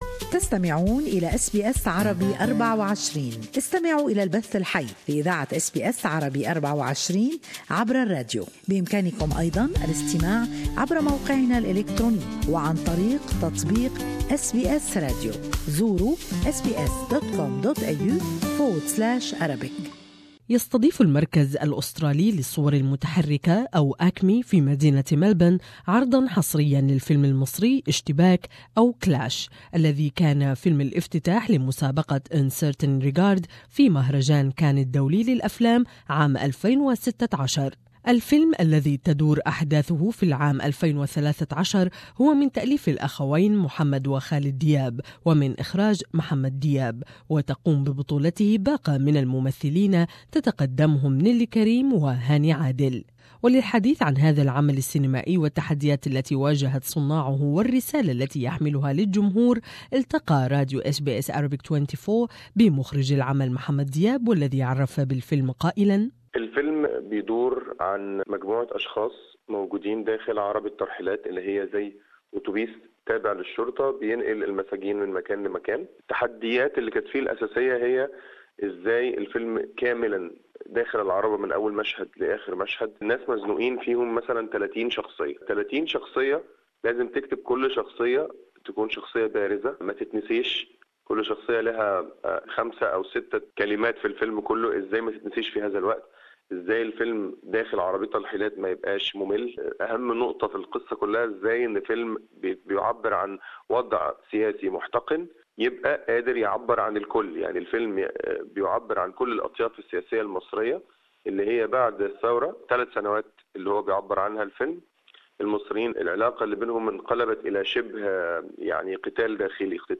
ACMI will screen Clash, the latest film from Mohamed Diab (Cairo 678), which opened Un Certain Regard at Cannes, will screen exclusively at ACMI Cinemas from Thursday 30 March. More in this interview with Director Mohamad Diab